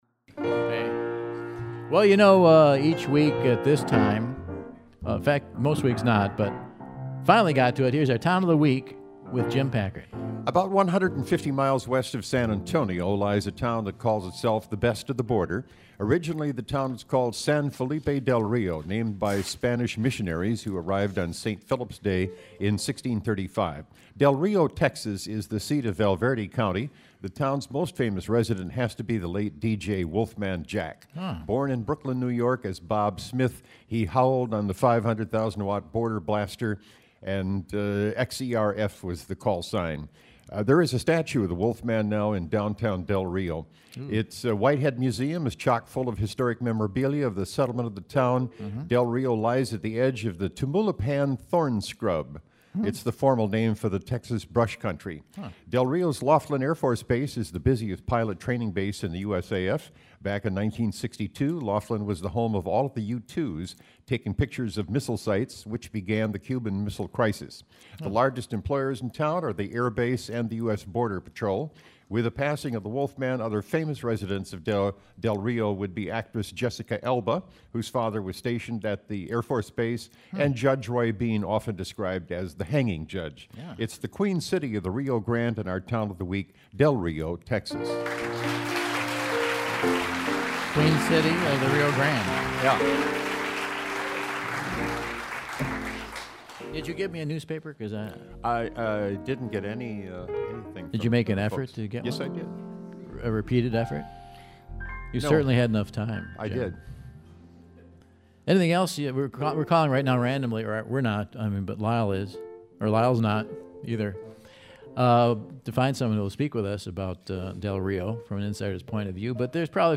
The golden-voiced